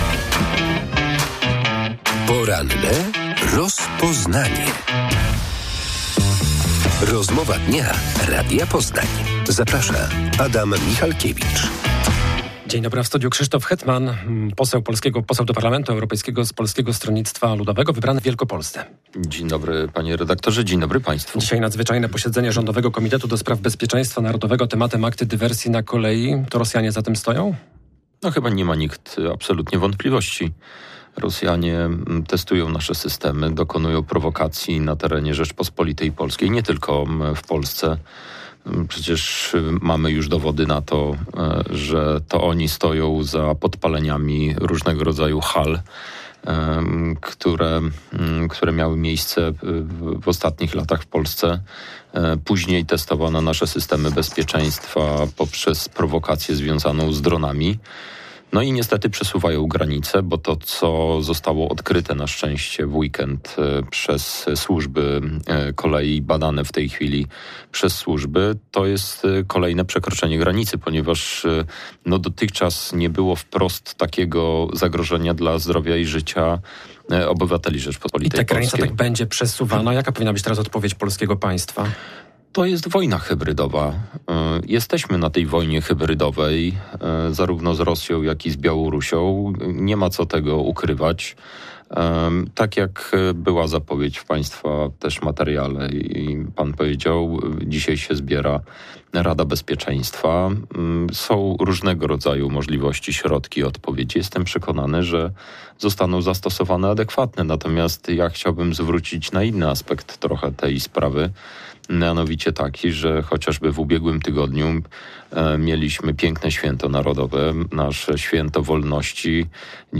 W mniejszych niż Poznań miastach działa metro - przekonywał w porannej rozmowie Radia Poznań poseł do Parlamentu Europejskiego Krzysztof Hetman z PSL. Jego zdaniem, jeżeli będzie wola mieszkańców i władz samorządowych to metro mogłoby powstać również w stolicy Wielkopolski.